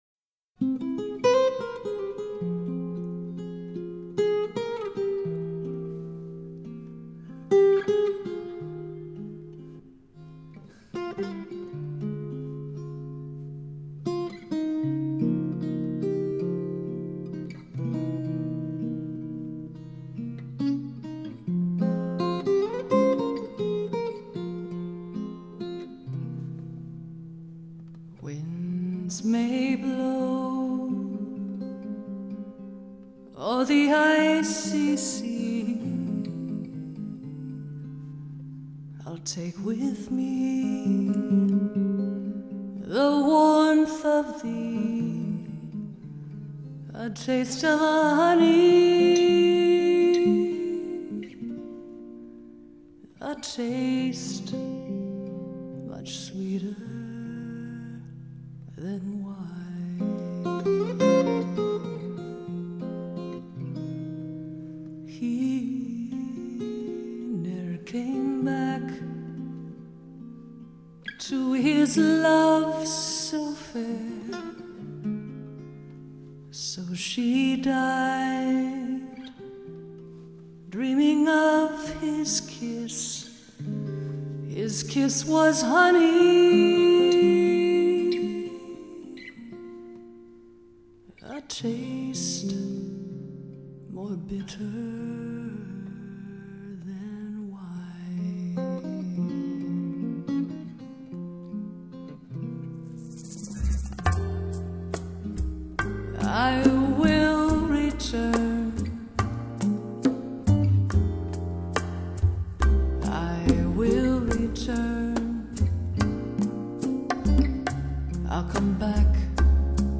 Jazz（女声）
这张谁也没想到会成为天碟的唱片在芝加哥一间录音室完成，前后只花了四天时间。
这张唱片中的音乐明显属于这样一种氛围，十分地道的蓝调节奏似醉迷离，女歌手的唱腔磁性而性感，艾艾怨怨，跌跌撞撞，粘粘糊糊。